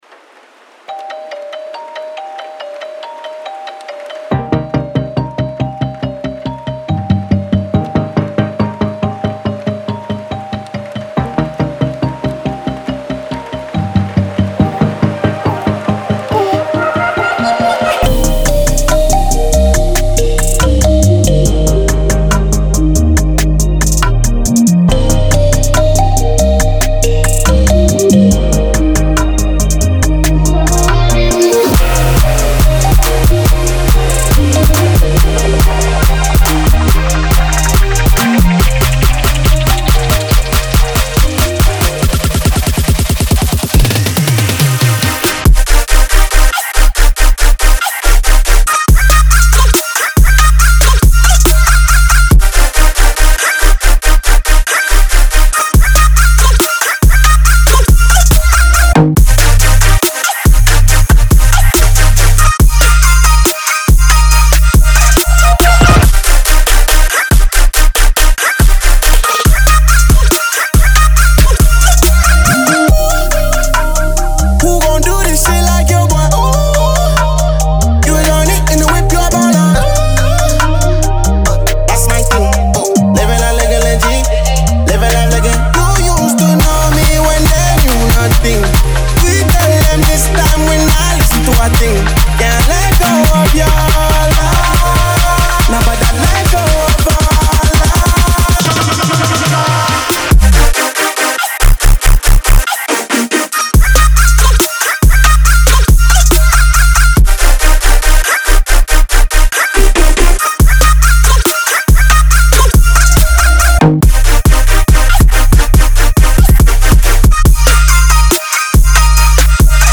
BPM140-140
Audio QualityPerfect (High Quality)
Dubstep / Trap song for StepMania, ITGmania, Project Outfox
Full Length Song (not arcade length cut)